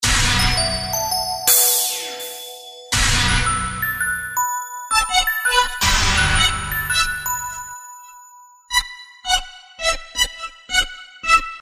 撞钟环路
描述：这首曲子以Hit合成器开始，然后是正常的和反转的钟声，用fl 8xxl完成按你的感觉使用
Tag: 83 bpm RnB Loops Synth Loops 1.95 MB wav Key : Unknown